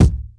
• Bumpin' Kickdrum Sample B Key 405.wav
Royality free bass drum tuned to the B note. Loudest frequency: 354Hz
bumpin-kickdrum-sample-b-key-405-Dds.wav